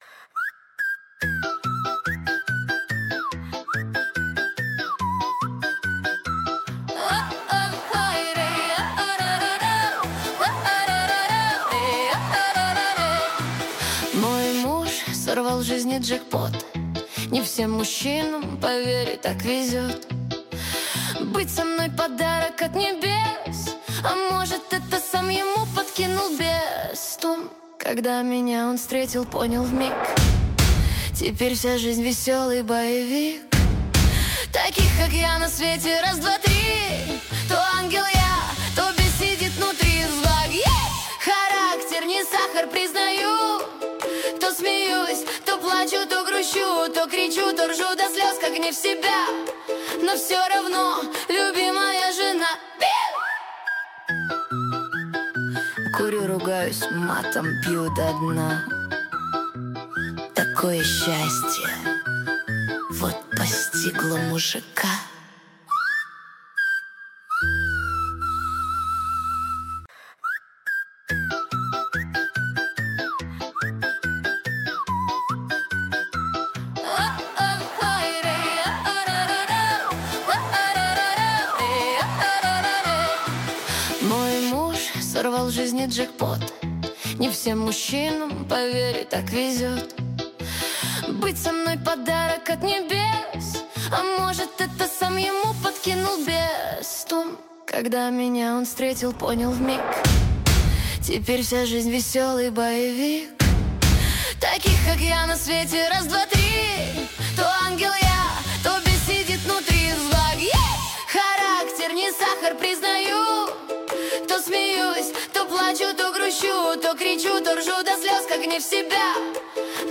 Нейросеть Песни 2025